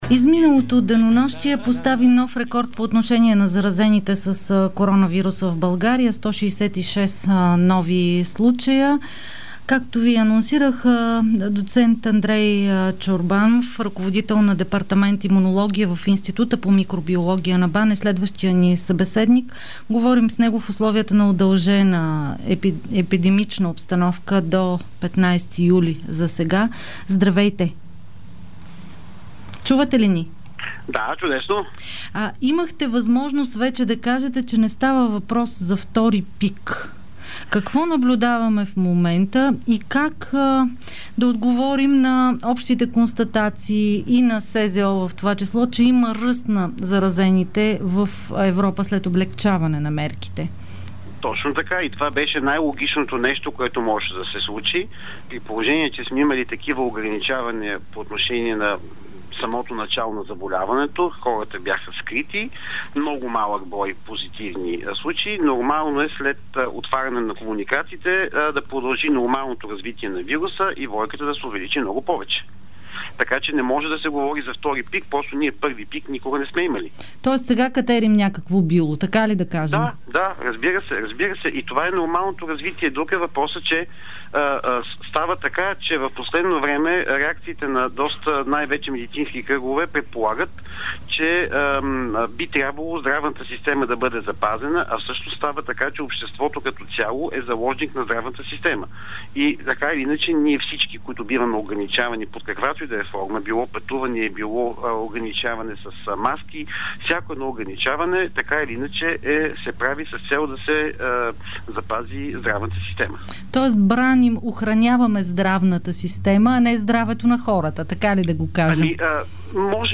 Ако един човек не се е срещнал с Ковид-19 през април, ще се срещне с него през август, допълни той в интервю за предаването на Дарик "Реактивно".